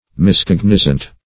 Miscognizant \Mis*cog"ni*zant\, a.